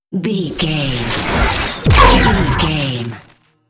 Whoosh to hit technology
Sound Effects
dark
futuristic
high tech
intense
tension
woosh to hit